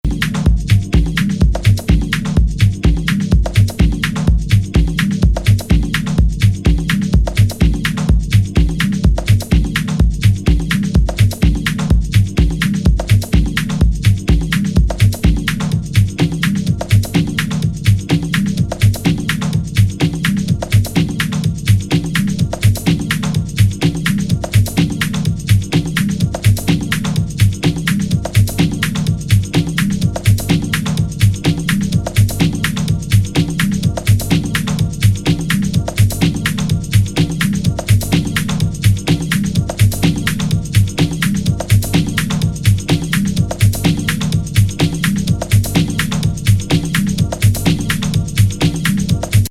がわかる最高のミニマル盤。